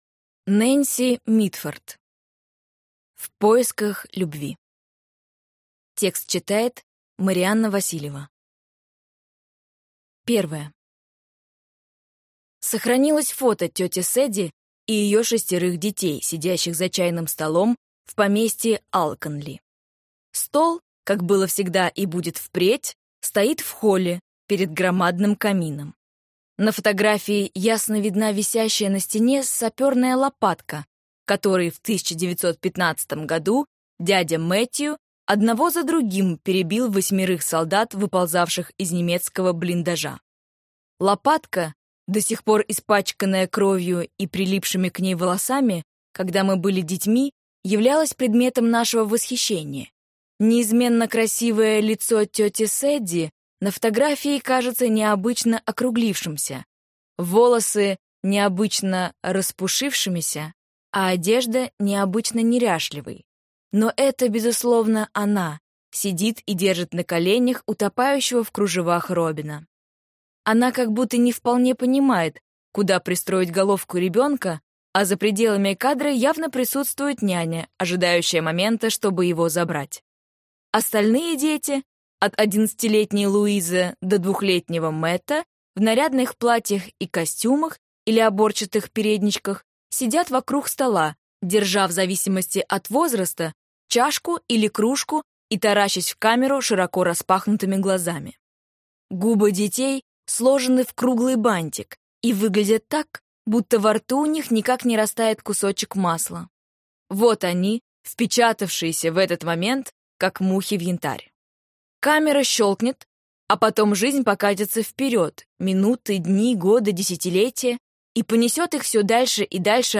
Аудиокнига В поисках любви | Библиотека аудиокниг